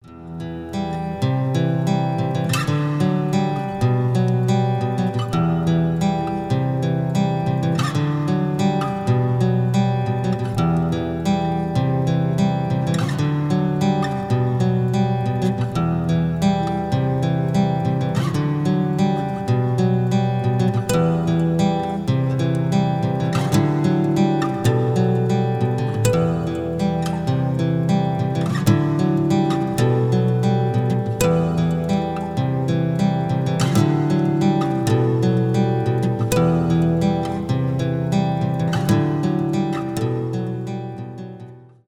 гитара
красивая мелодия , инструментальные , без слов